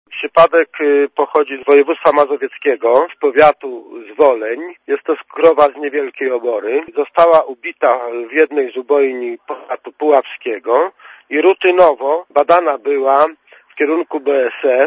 Mówi wojewódzki lekarz weterynarii w Lublinie, Jan Sławomirski